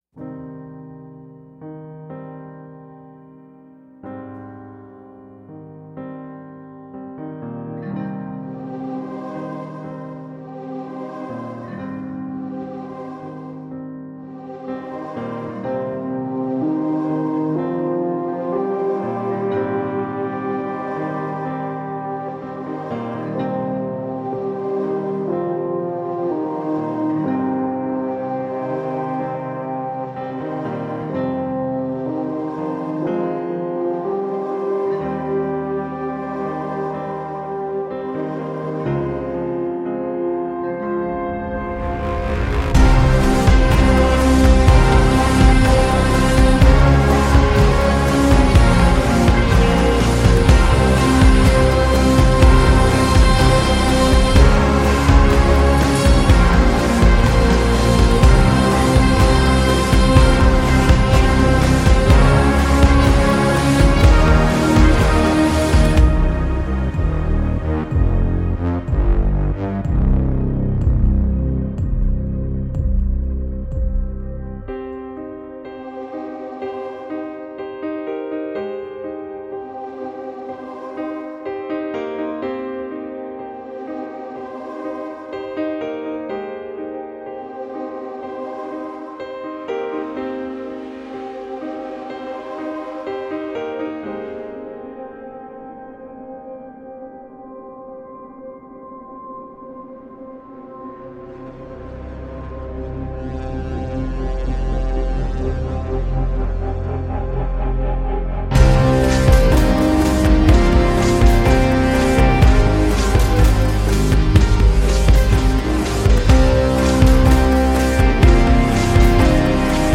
pulsations et de nappes électroniques